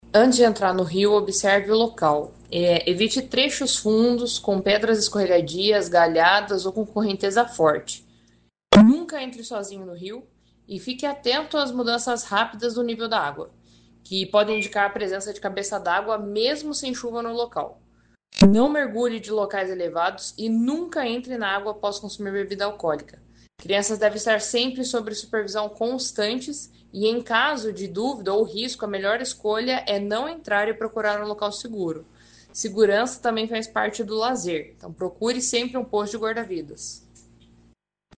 Sonora da capitão do CBMPR